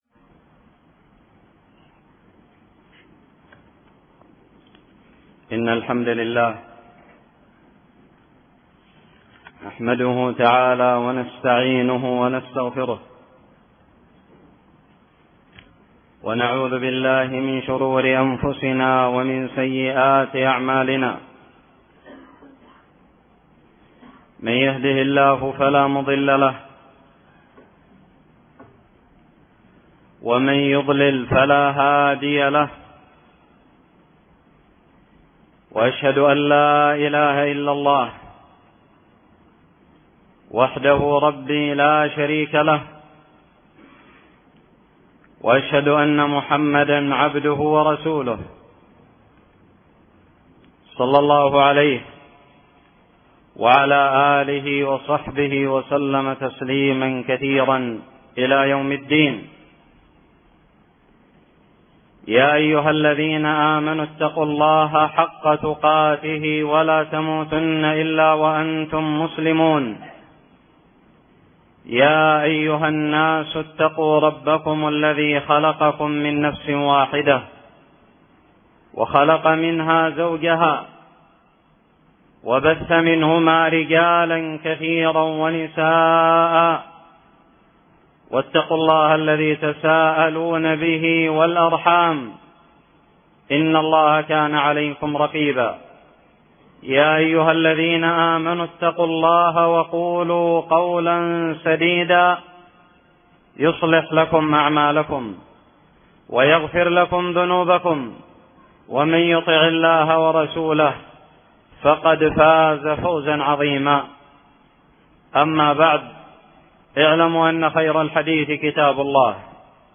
خطب الجمعة
ألقيت بدار الحديث السلفية للعلوم الشرعية بالضالع في 6 شوال 1438هــ